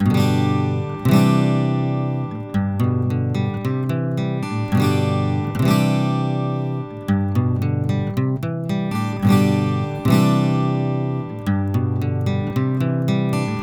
Pour les prises de son, j’ai utilisé un préamplificateur Neve 4081 quatre canaux avec la carte optionnelle Digital l/O qui convertit l’analogique en numérique AES sur une SubD25 ou en Firewire.
Les échantillons n’ont subi aucun traitement.
Prise de son 3 : LCT640 – DPA 4011.
Enregistrements d’une guitare acoustique (AIFF) :
Lewitt640_Prise3.aif